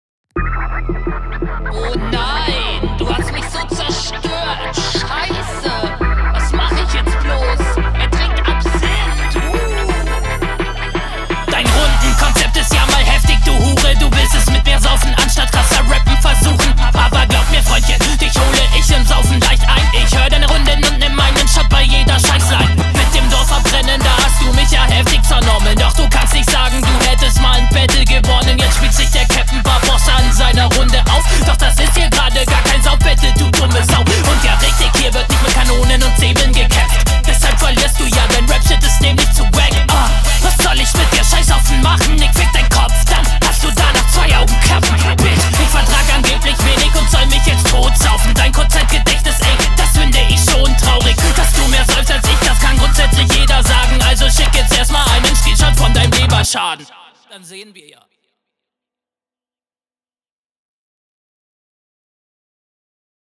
Beat goes like aua
der beat ist viel zu laut und tut weh. sounddesign an sich klar überlegen, aber …